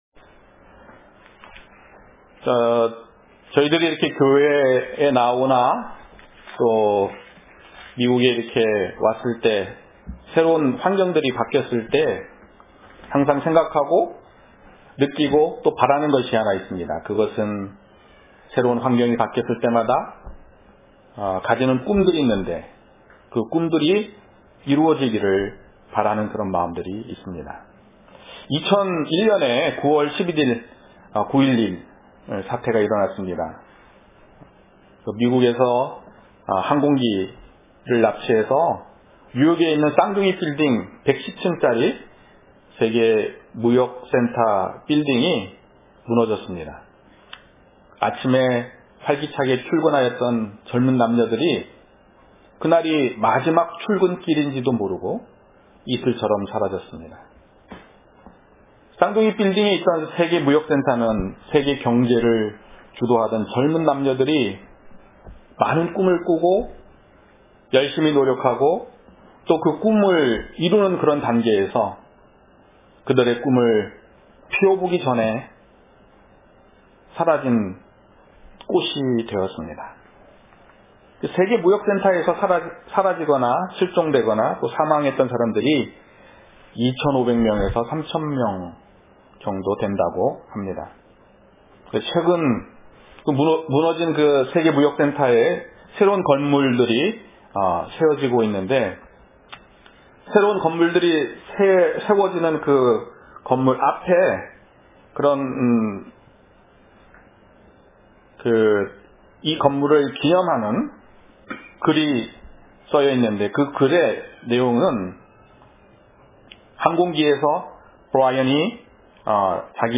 본문 : 고린도전서2장 1절~8절 설교